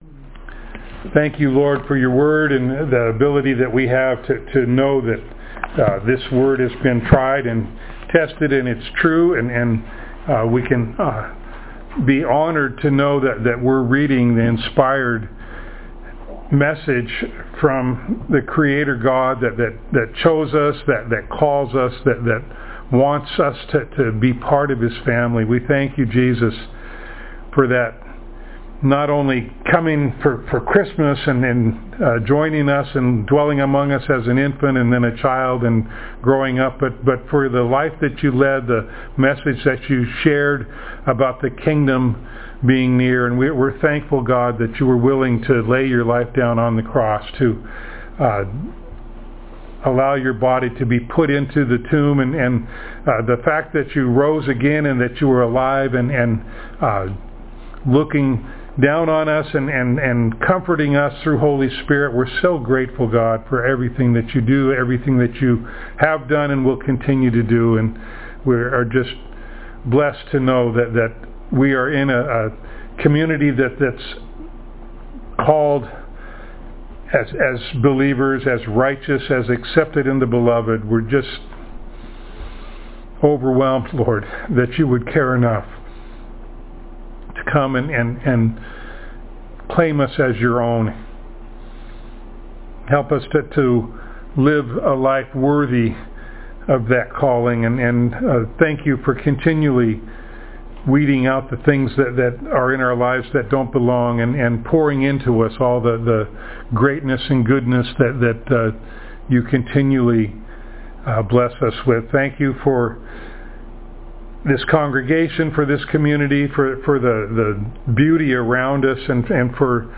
Passage: Luke 23:50-24:12 Service Type: Sunday Morning